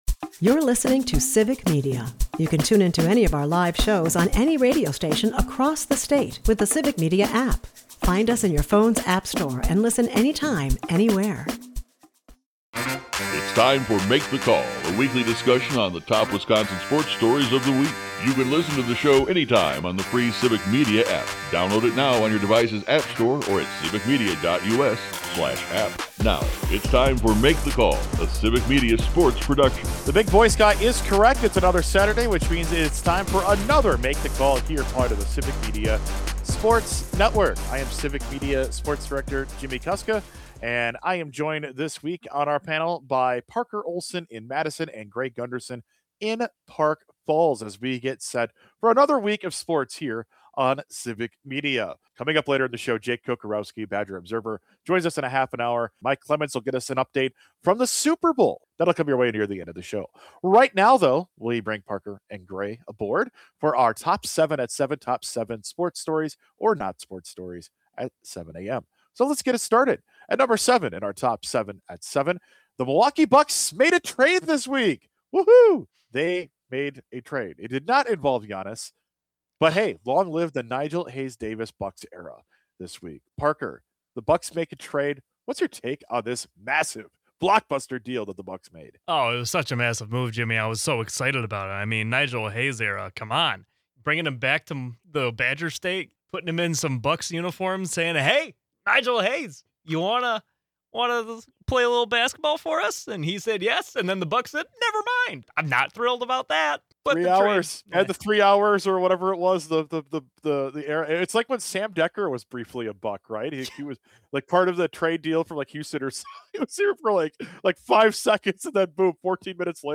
The NBA trade deadline has passed and the Greek Freak is still with the Bucks. The roundtable talks about Giannis as well as the other massive sporting events in the world, like the Olympics and the Super Bowl.